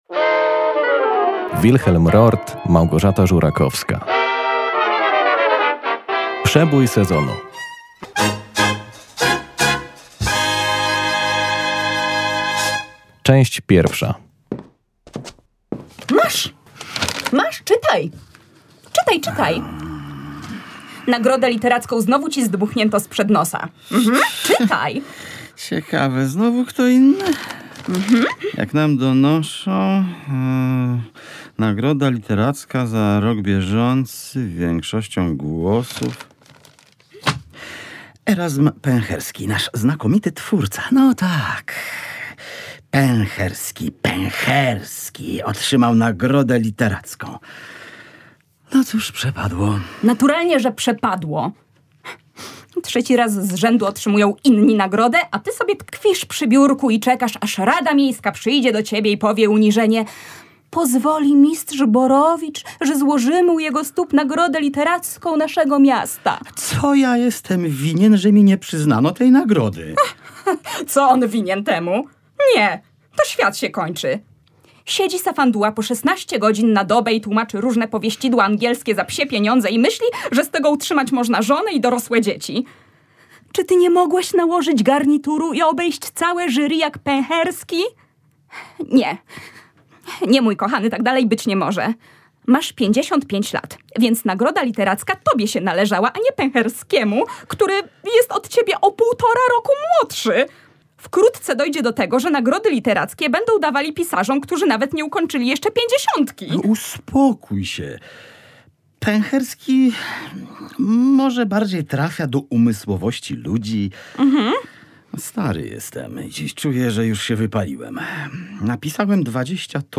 W programie pierwsza część słuchowiska „Przebój sezonu”, którego bohaterem jest uznany literat i jego rodzina…